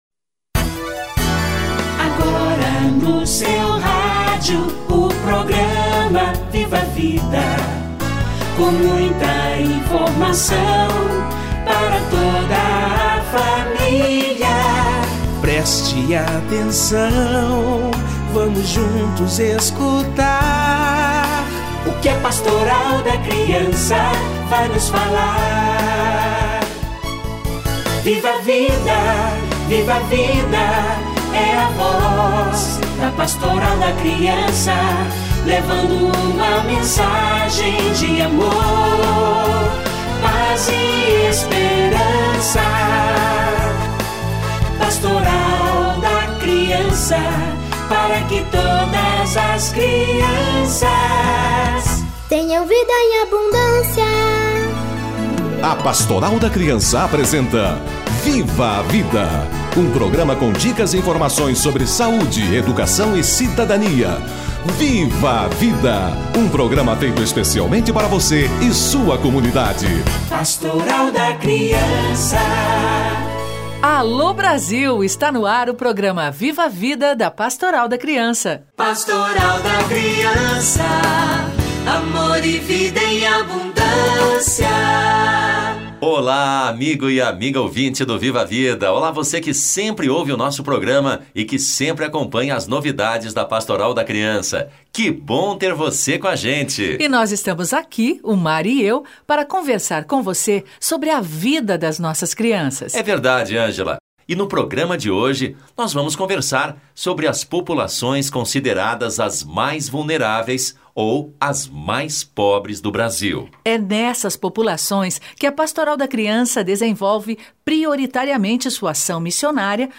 Pastoral da Criança e populações em situação de vulnerabilidade - Entrevista